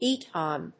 /ˈitʌn(米国英語), ˈi:tʌn(英国英語)/